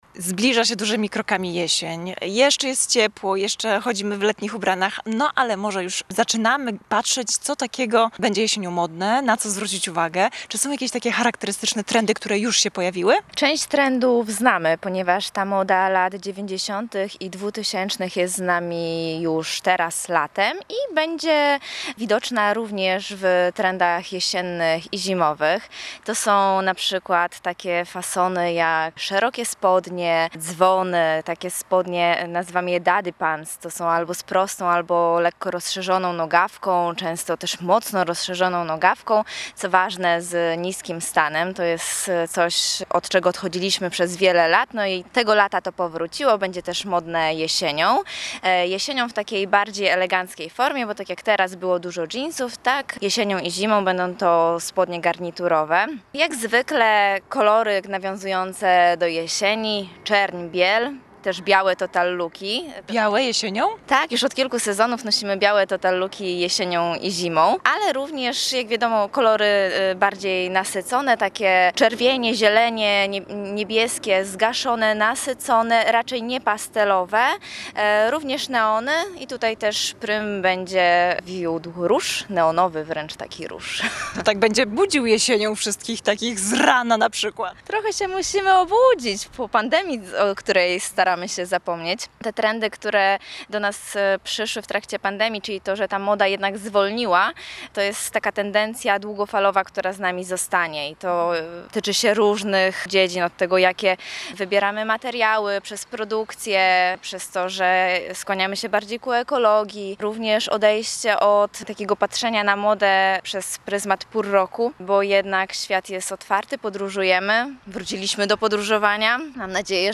O to spytaliśmy jedną z gdańskich influencerek.